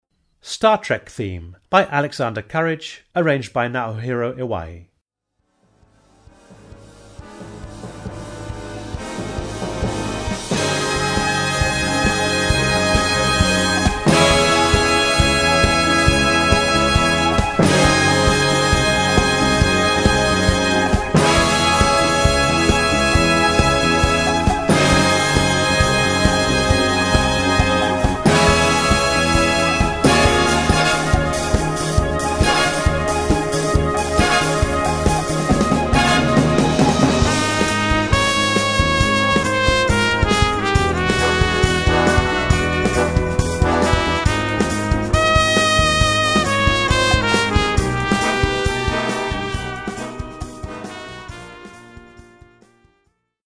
Gattung: Filmmelodie
Besetzung: Blasorchester